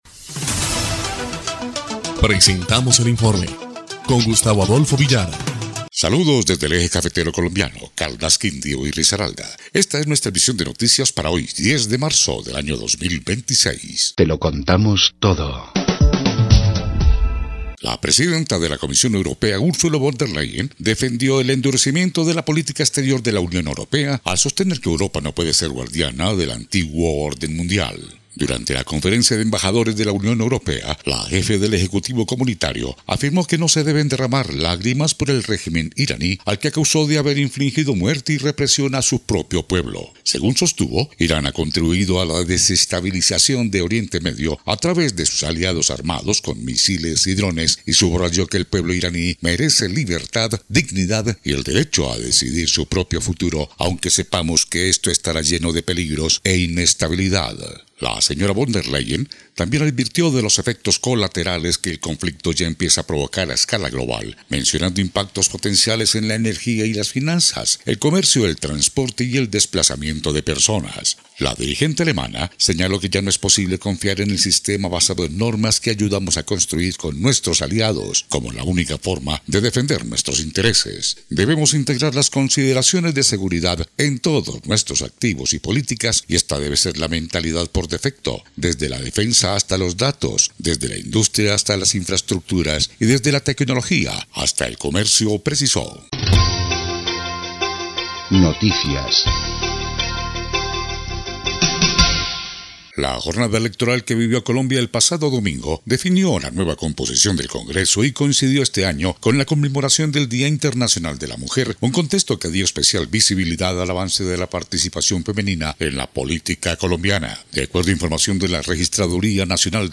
EL INFORME 3° Clip de Noticias del 10 de marzo de 2026